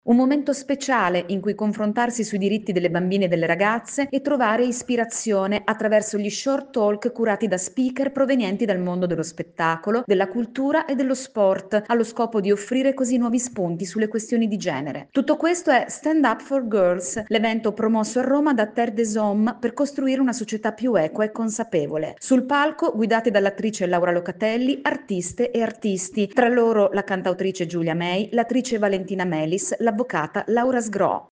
Nell’ambito del Festival dello Sviluppo Sostenibile promosso da ASviS, appuntamento questa sera a Roma con “Stand up for girls”. Il servizio